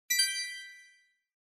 newmessage.mp3